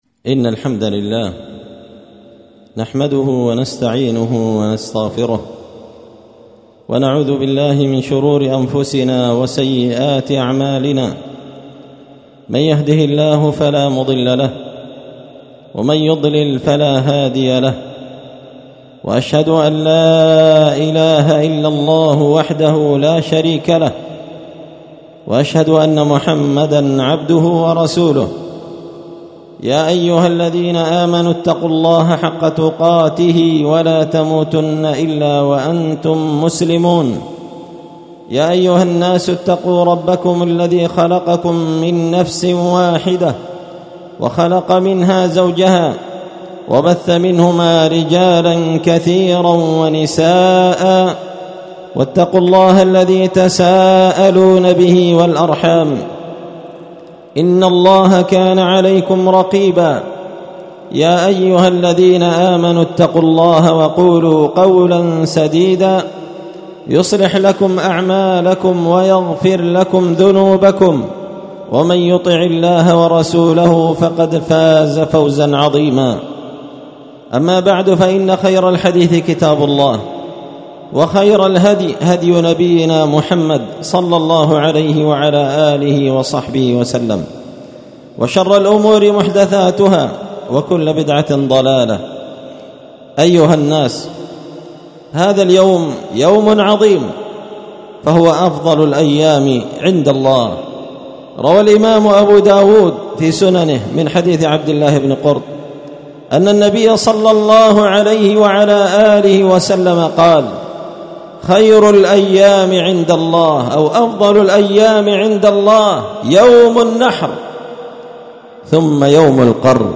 ألقيت هذه الخطبة في مصلى أهل السنة والجماعة قشن-المهرة-اليمن تحميل…
خطبة عيد الأضحى المبارك
خطبة-عيد-الأضحى-المبارك-لعام-1444هــ.mp3